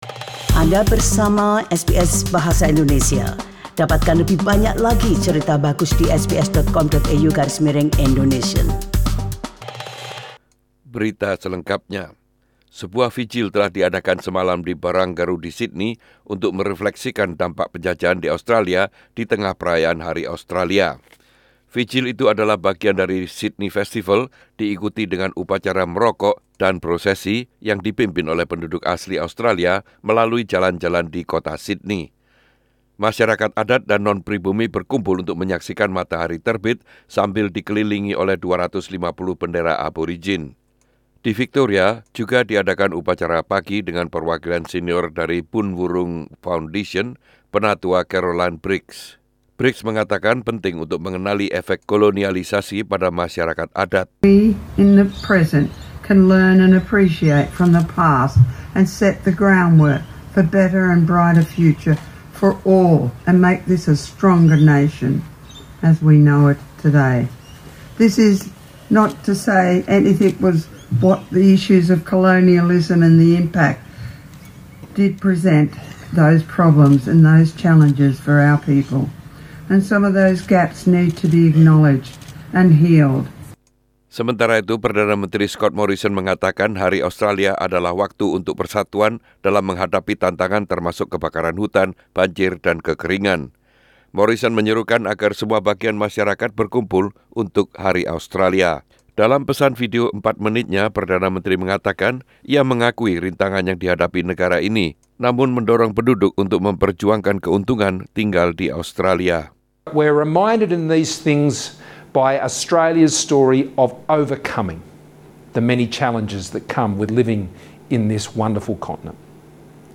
Warta Berita Radio SBS dalam Bahasa Indonesia - 26 Januari 2020